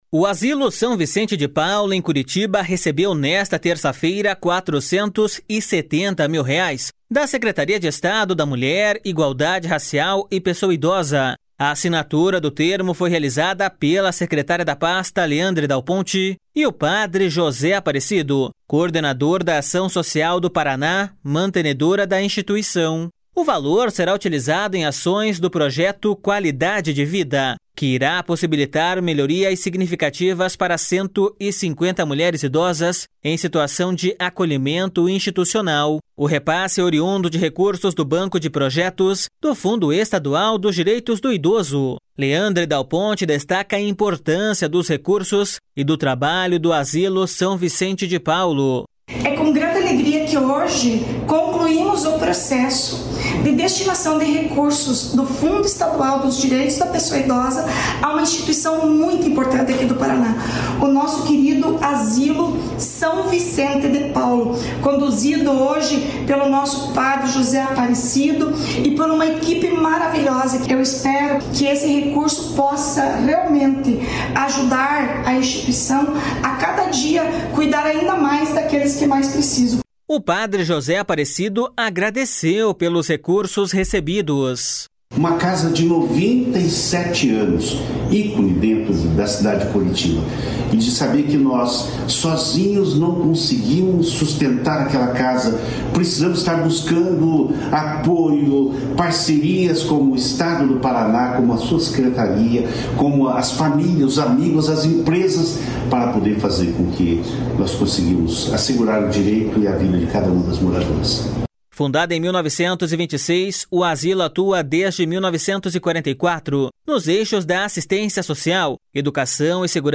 Leandre Dal Ponte destaca a importância dos recursos e do trabalho do Asilo São Vicente de Paulo.// SONORA LEANDRE DAL PONTE.//